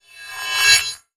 time_warp_reverse_spell_06.wav